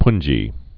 (pnjē, pŭn-)